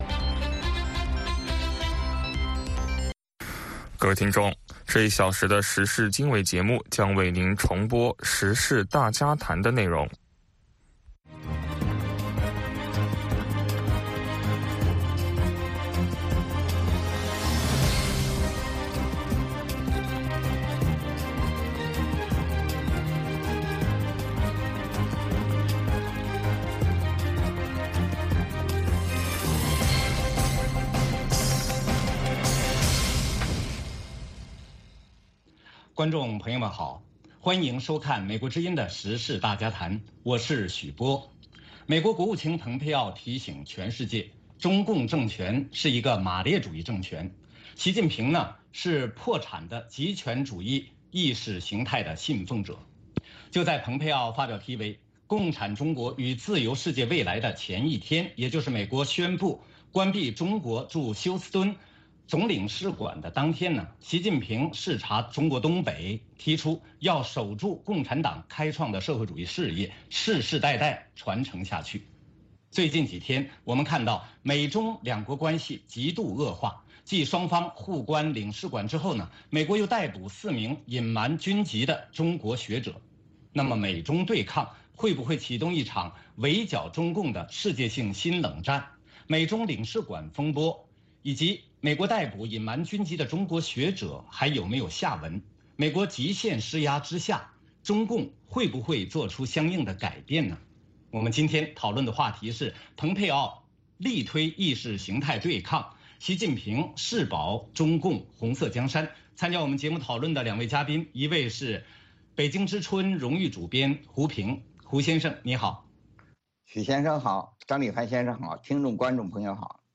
美国之音中文广播于北京时间每天晚上7-8点播出《时事经纬》节目。《时事经纬》重点报道美国、世界和中国、香港、台湾的新闻大事，内容包括美国之音驻世界各地记者的报道，其中有中文部记者和特约记者的采访报道，背景报道、世界报章杂志文章介绍以及新闻评论等等。